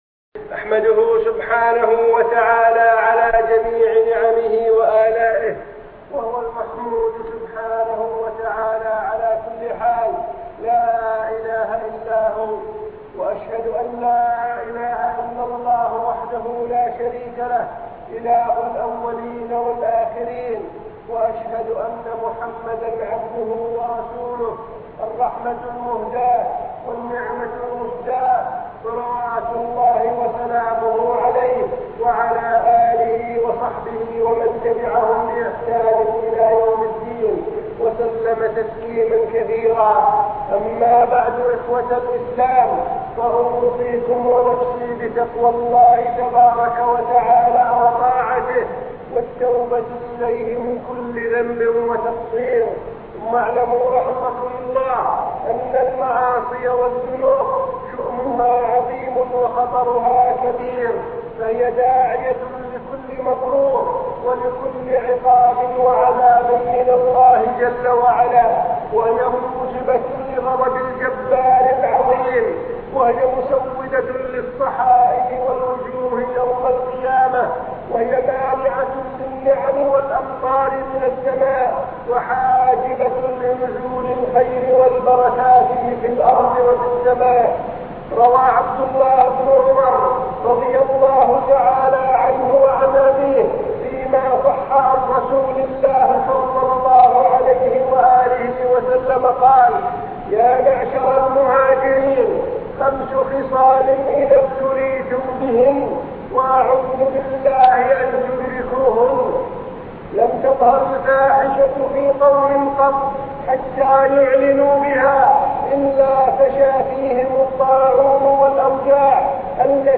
خطبة قديمة للشيخ حفظه الله